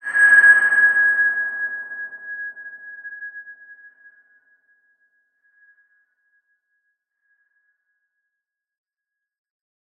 X_BasicBells-G#4-ff.wav